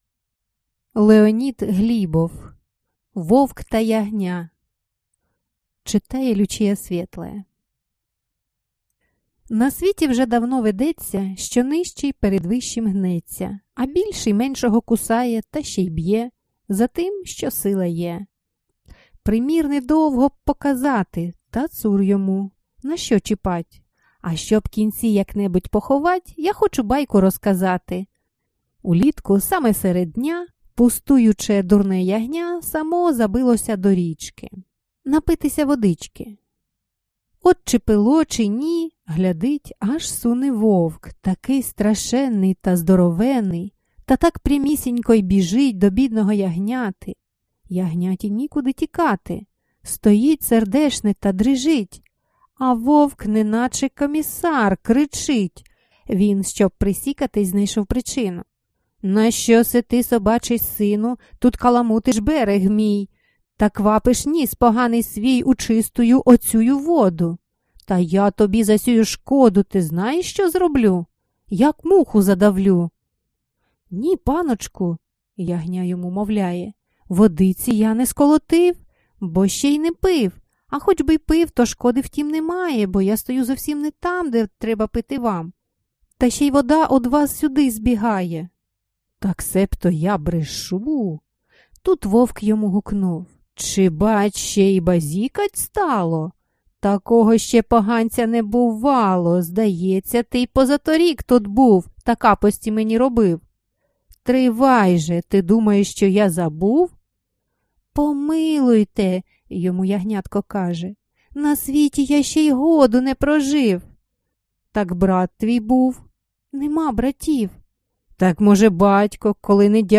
Аудиокнига Байки (Збірка) | Библиотека аудиокниг